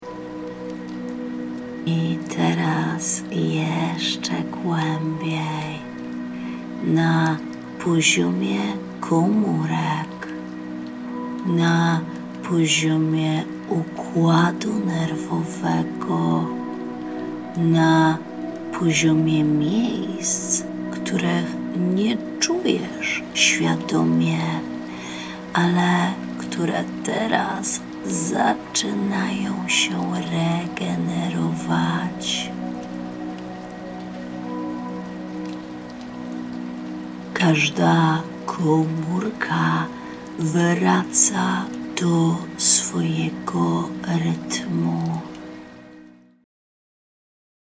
BAŃKA: Oczyszczenie to rytuał hipnotyczny na wieczór.